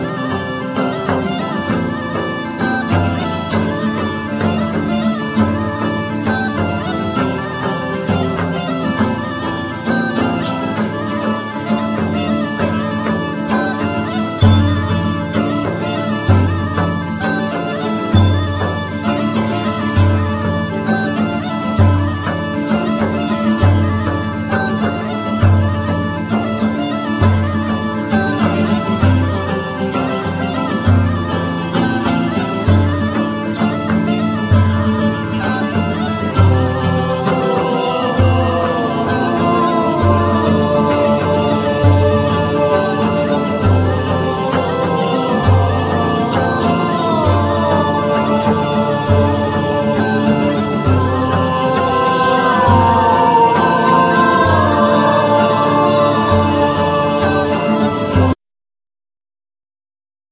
Ancient greek instruments